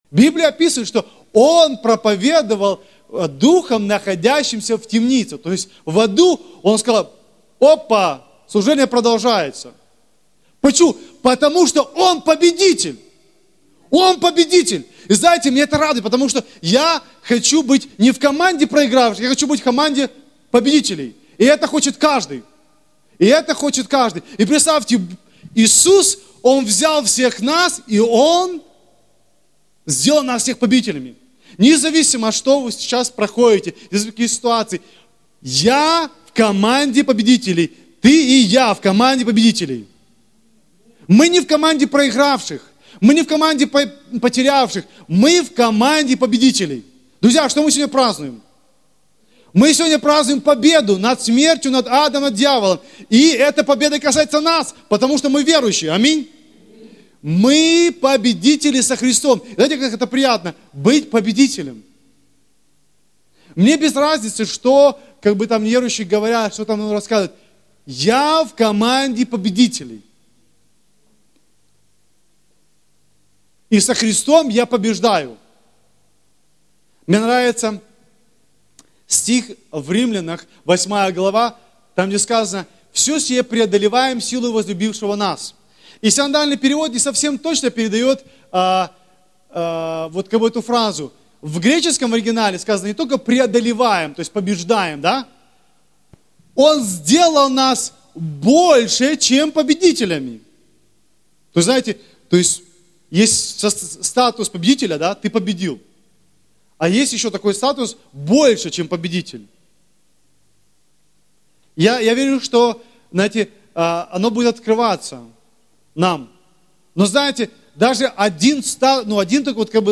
Актуальная проповедь